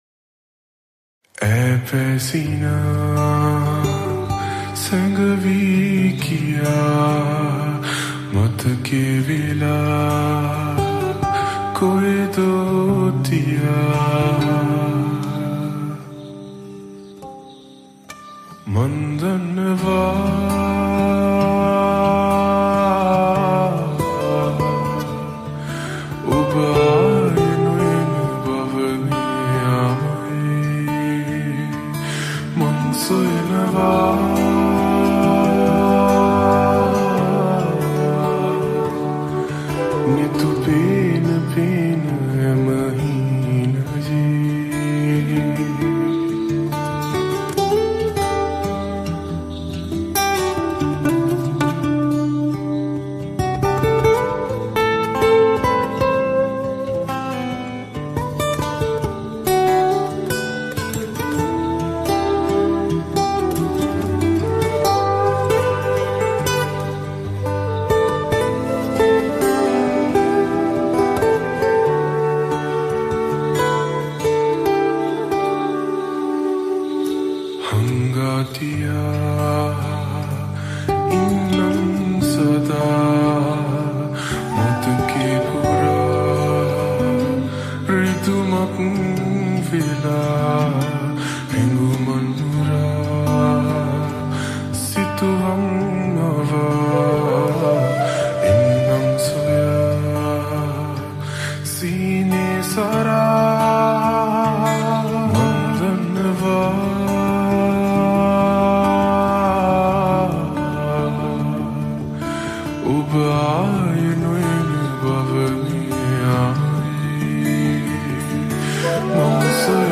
Slowed & Reverb
8d sound